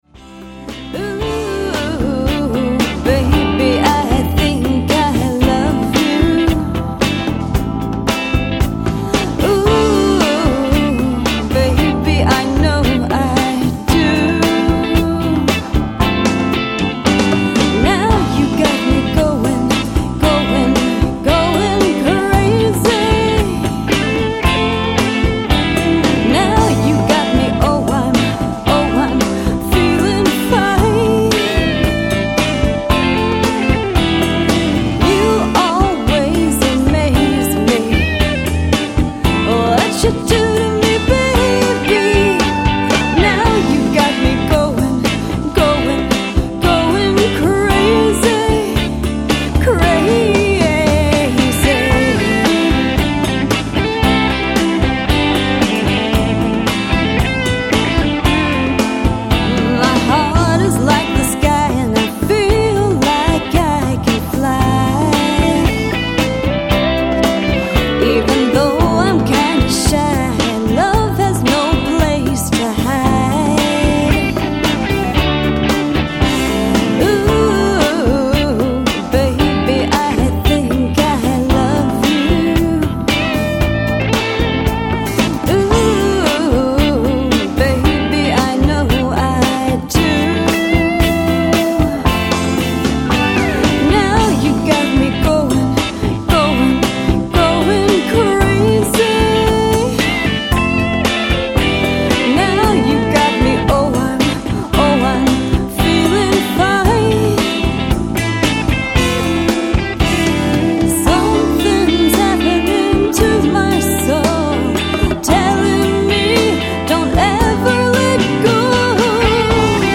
jazzy rock/blues album
stirring, sensuous